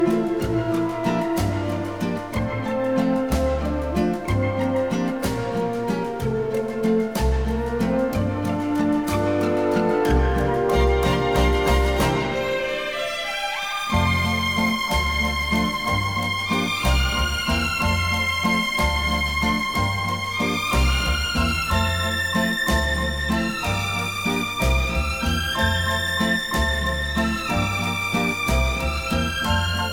Жанр: Джаз / Классика